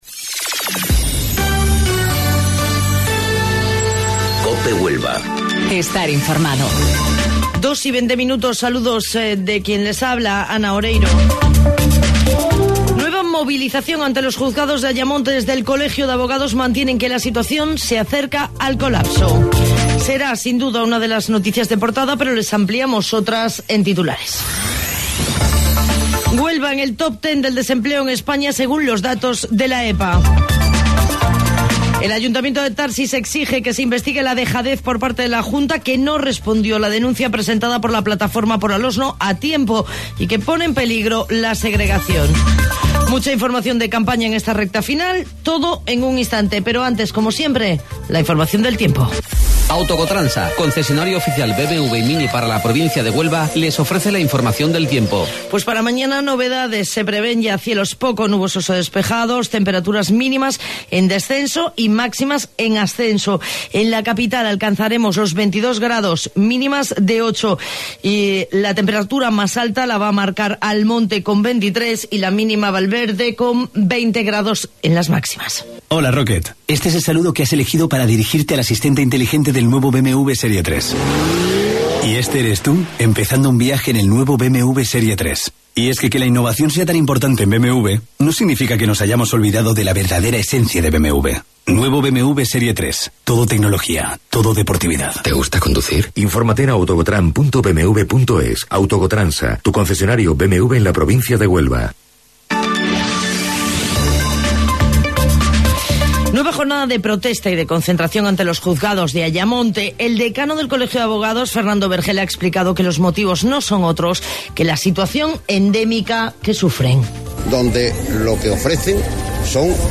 AUDIO: Informativo Local 14:20 del 25 de Abril